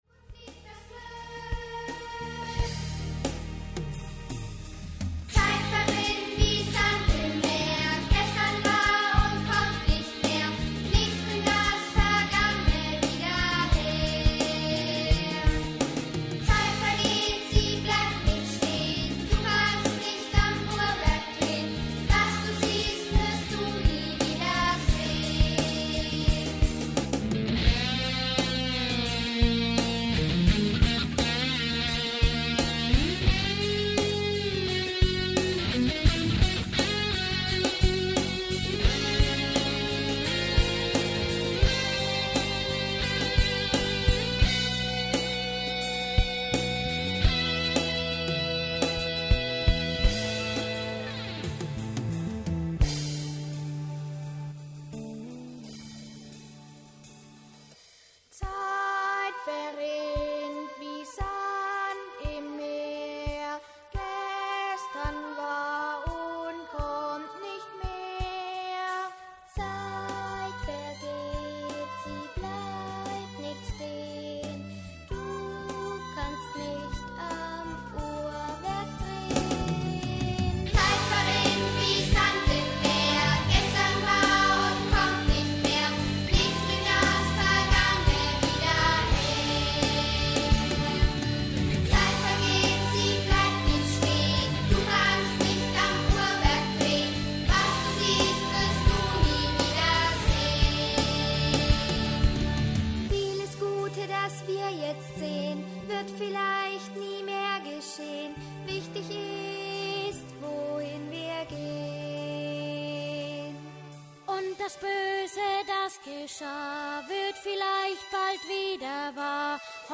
Musical in zwei Akten für Jugendchor und Begleitung
Chor: Chor einstimmig, Backgroundchor ein- bis dreistimmig